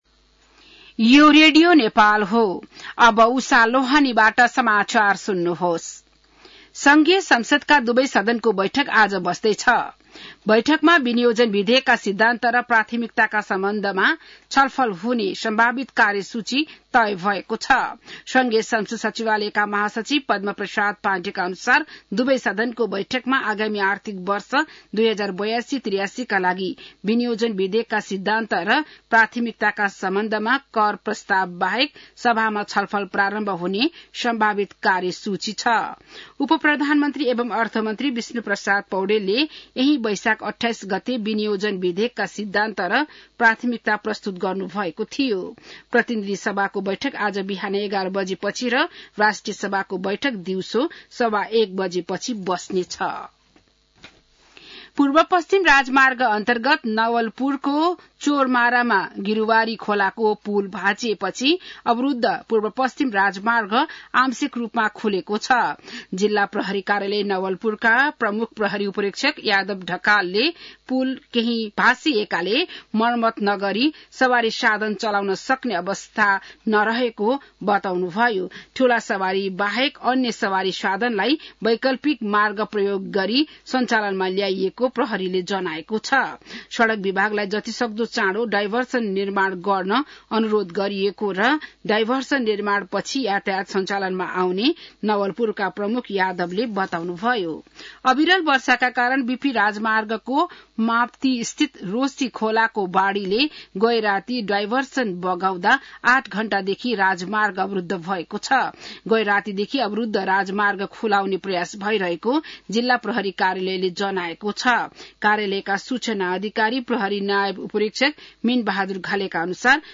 बिहान १० बजेको नेपाली समाचार : ३० वैशाख , २०८२